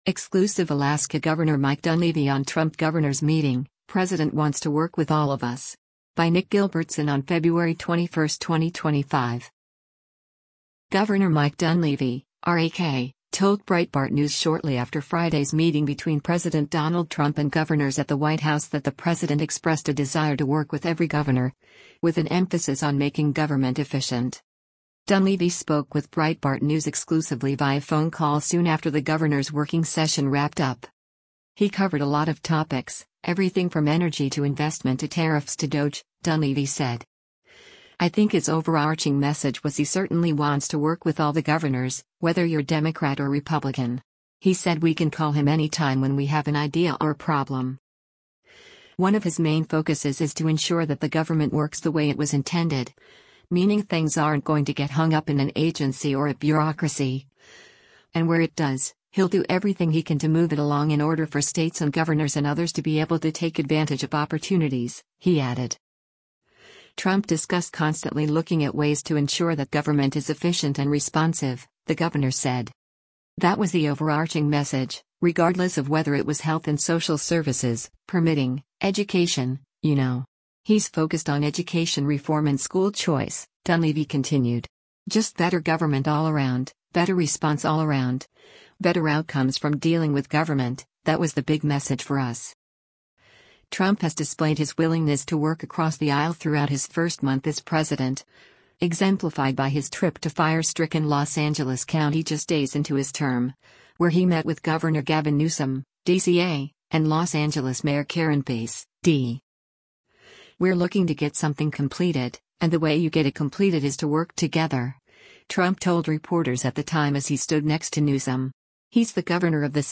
Dunleavy spoke with Breitbart News exclusively via phone call soon after the Governors’ Working Session wrapped up.